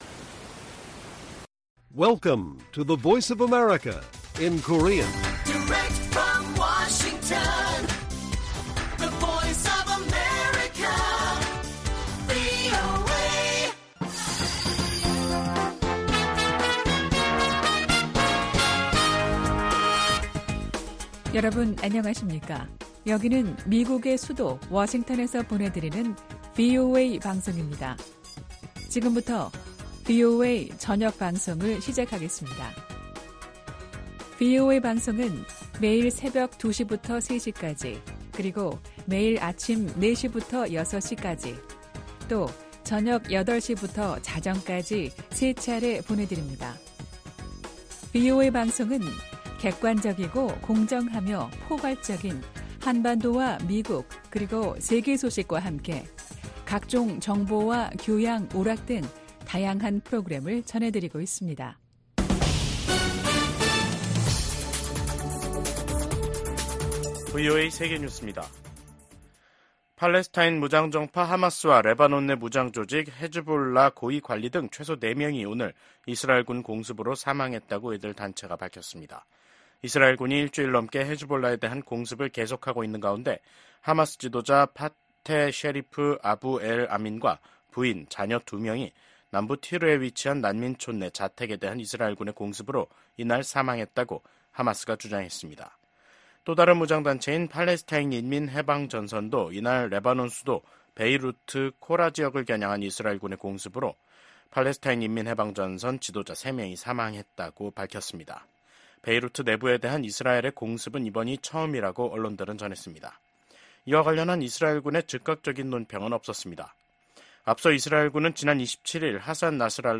VOA 한국어 간판 뉴스 프로그램 '뉴스 투데이', 2024년 9월 30일 1부 방송입니다. 미국 국무장관이 북한을 압박해 러시아에 대한 무기 제공을 중단시켜야 한다고 강조했습니다. 미 국무부는 북한이 사실상 핵보유국이라는 국제원자력기구(IAEA) 수장의 발언과 관련해 한반도의 완전한 비핵화 목표를 다시 한 번 확인했습니다. 한국 정부는 북한 비핵화 목표를 부정한 러시아 외무장관의 발언에 대해 무책임하다고 비판했습니다.